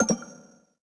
snd_ui_no.wav